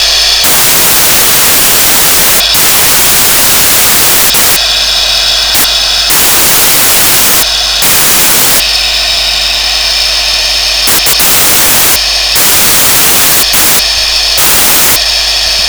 When chuffer is left idle steam sound hiss dies down after approx. 10 seconds and builds up again before moving off